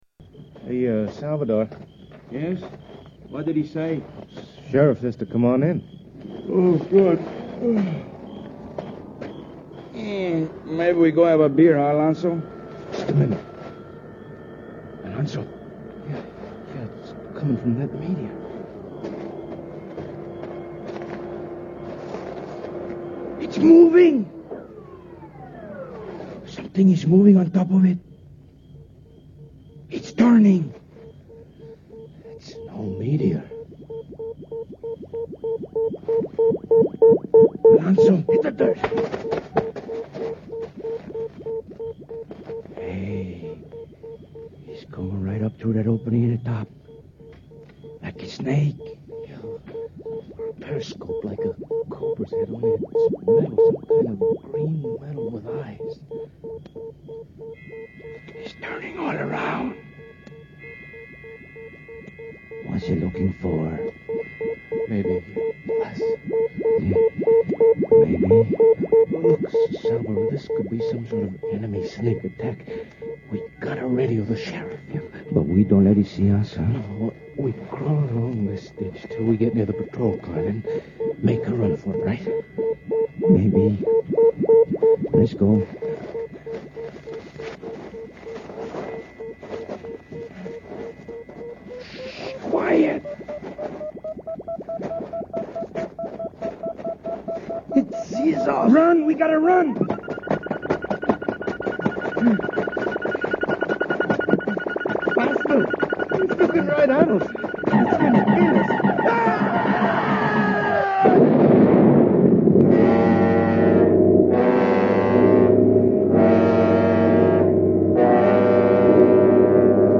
The Lux Radio Theatre version of The War of the Worlds was broadcast on the 8th of February 1955, less than 6 months before the curtain finally fell on the show. Dana Andrews and Pat Crowley took on the roles made famous by Gene Barry and Ann Robinson in the 1953 George Pal movie and are every bit as good as their celluloid predecessors, though it's fun to hear Crowley mangle her pronunciation of the word Nuclear.
The sound is also superb, with the original Martian Heat Ray effect reused to great effect.